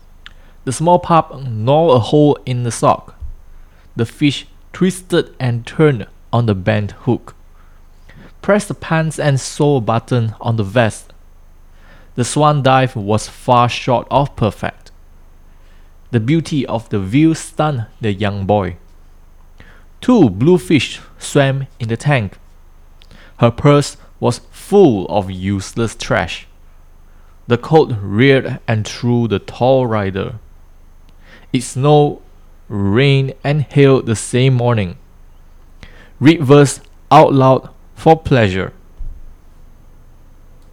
Sound Test
This is a raw sample recording without any filters or editing. The audio profile is more on the bassy side and most importantly, it has done a great job at capturing the mid-tones which is crucial for podcast and live streaming.
FIFINE-K688-Audio-Sample.mp3